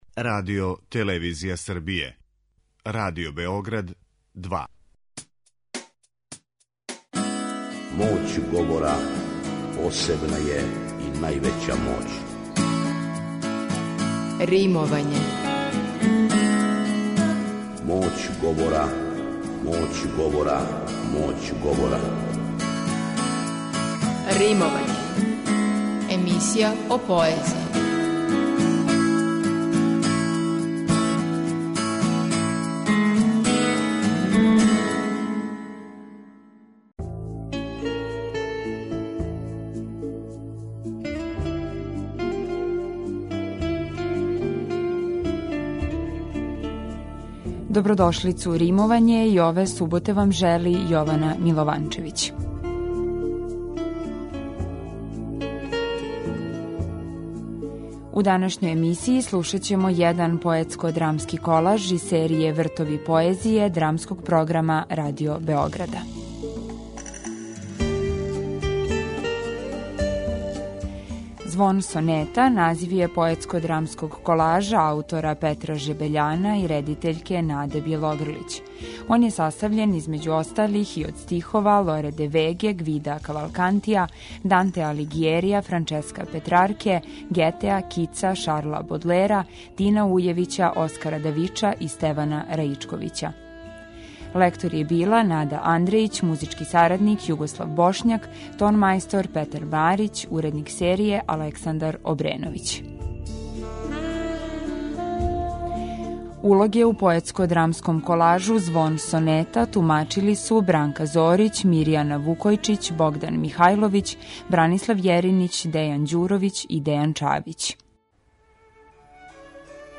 Поетско-драмски колаж „Звон сонета"